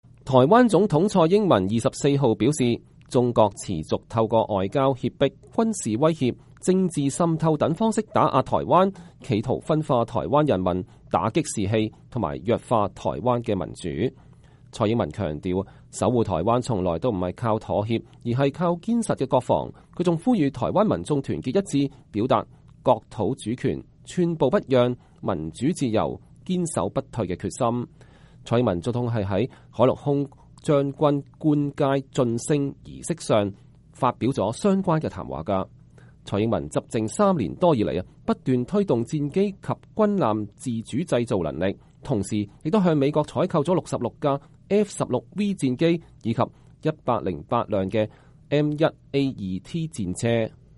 蔡英文總統是在陸海空軍將官晉任布達暨授階典禮上發表了相關談話。